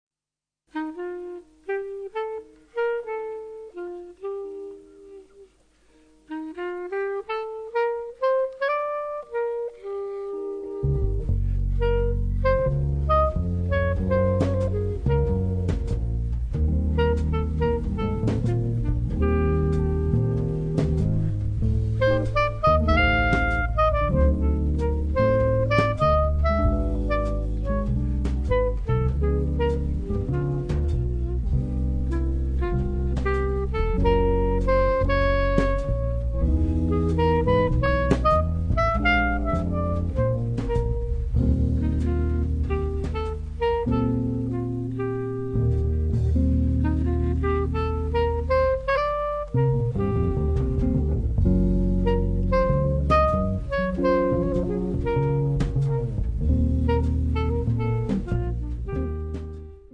Live in Poggio alla Croce (Fi), 23 luglio 2005
clarinetto
chitarra
contrabbasso
batteria